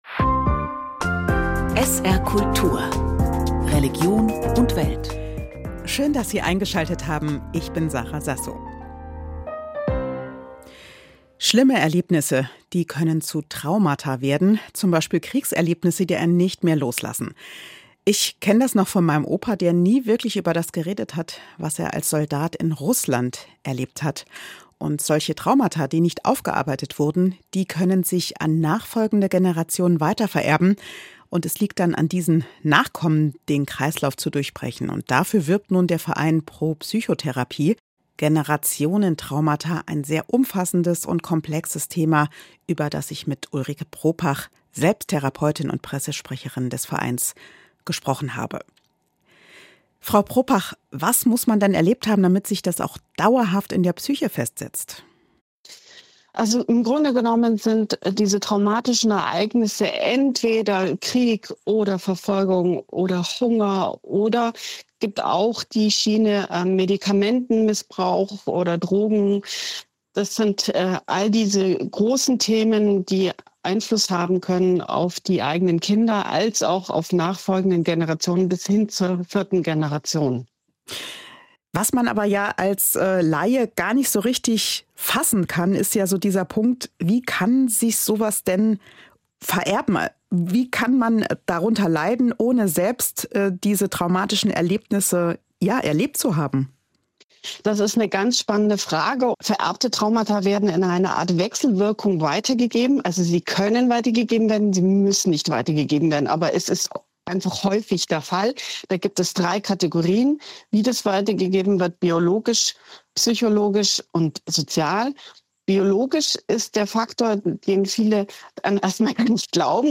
"Religion und Welt" ist eine von der Religionsredaktion des SR gestaltete Magazinsendung mit regionalen und überregionalen Themen an der Schnittstelle von Glaube, Kirche und Gesellschaft.